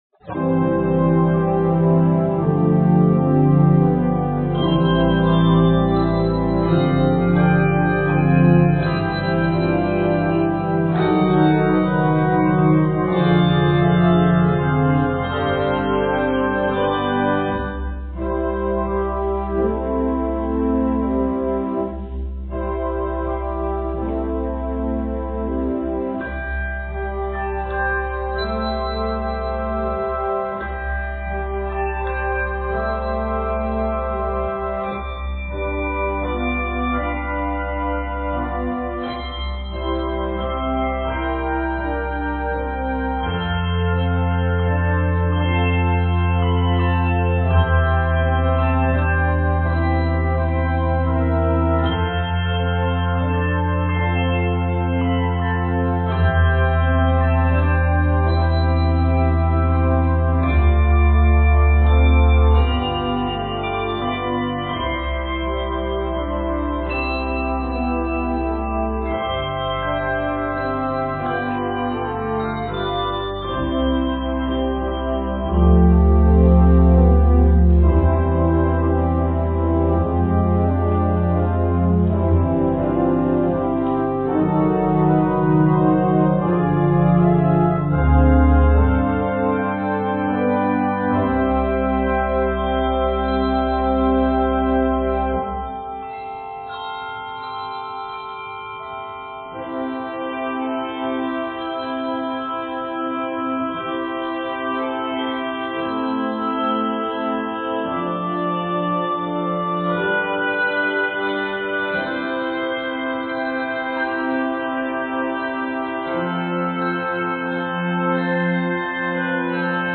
This meditative arrangement
This music has been set in the keys of C Major and D Major.